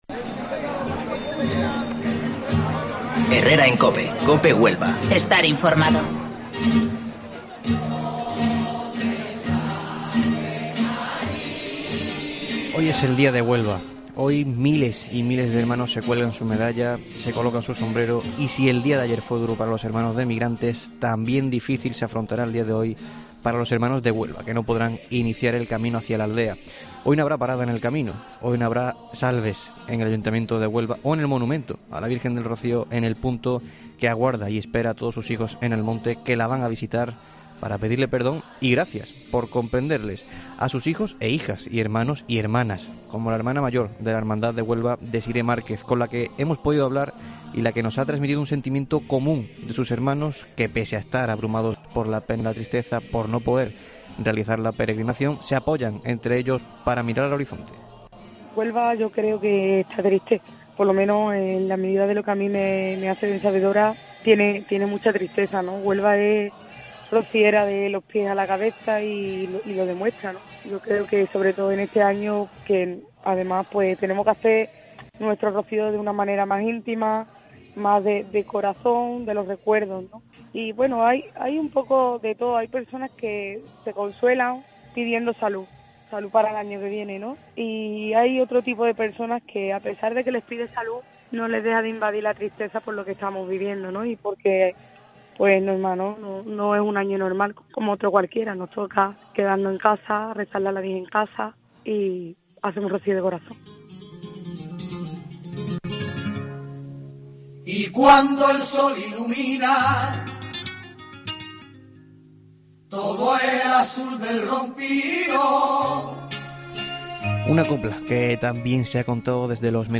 Comenzamos el 2º día de programación especial en este Rocío 2020 con testimonios de hermanos de Huelva que nos analizan su sentir en el tiempo local de Herrera en COPE.